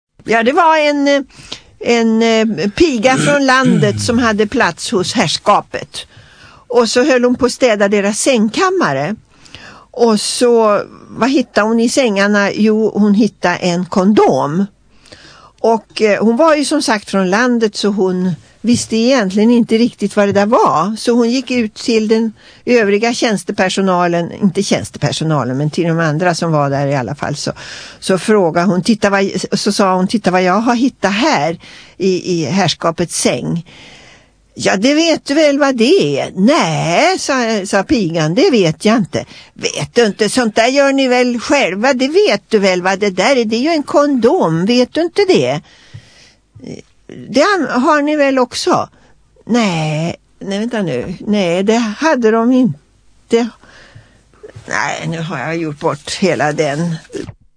Mormor berättar en jätterolig historia
Lo-Fi